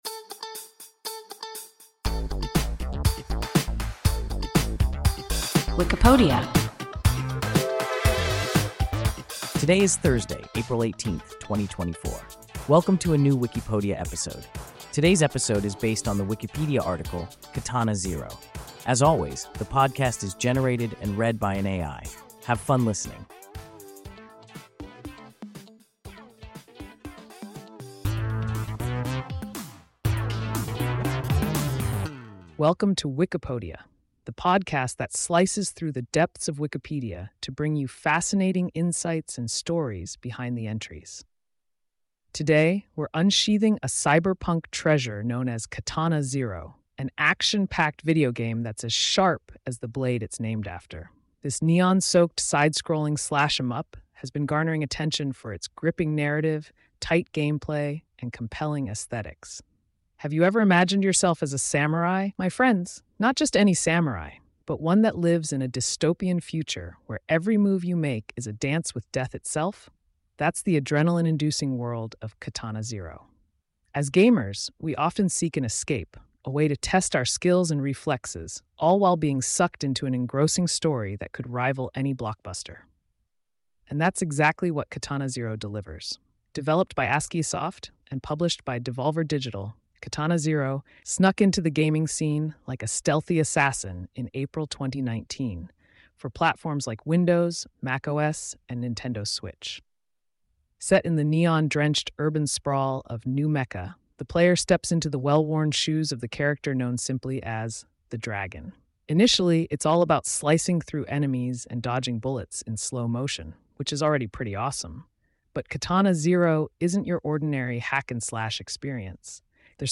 Katana Zero – WIKIPODIA – ein KI Podcast